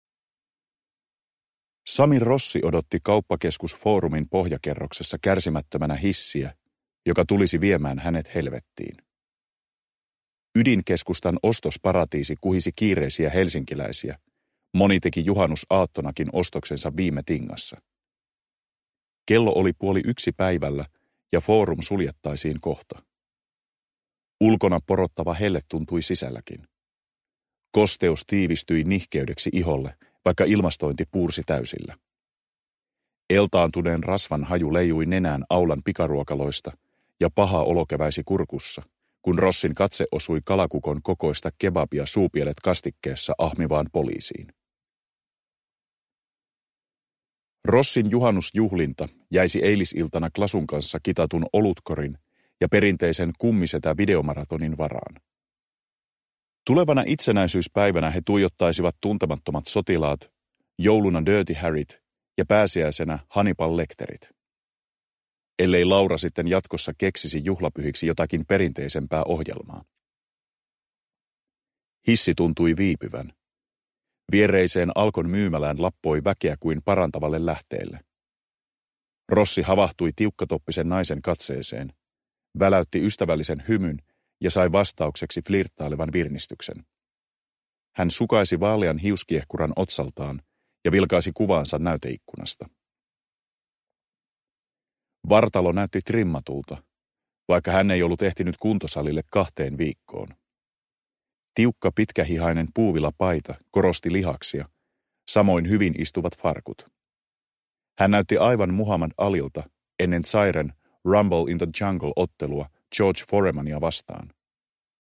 Ikuisesti paha – Ljudbok – Laddas ner